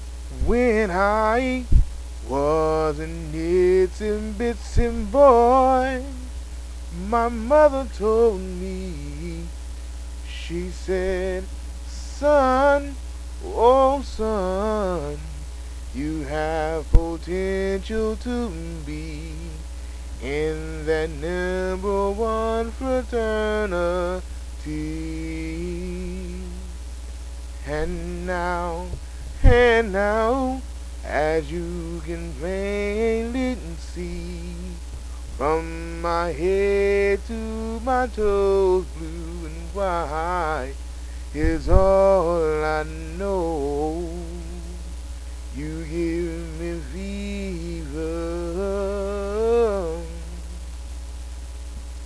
Sigma Chapter Chants